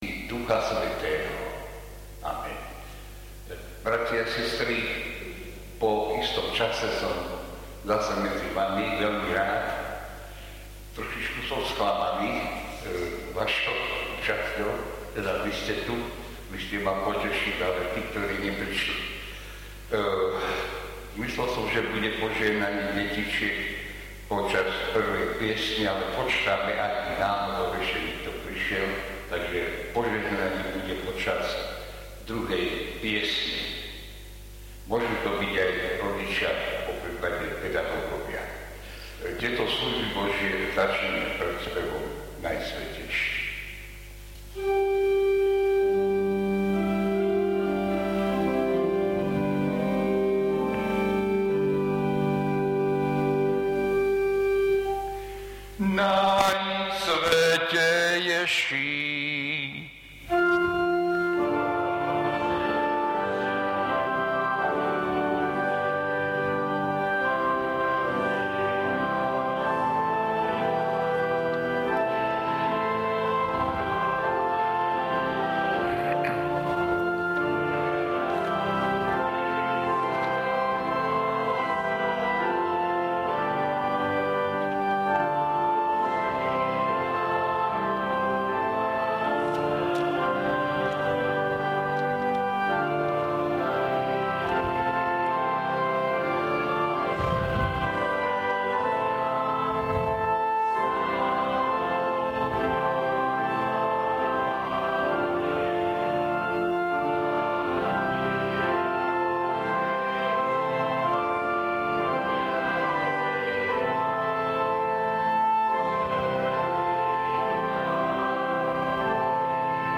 Služby Božie – 11. nedeľa po Sv. Trojici
V nasledovnom článku si môžete vypočuť zvukový záznam zo služieb Božích – 11. nedeľa po Sv. Trojici.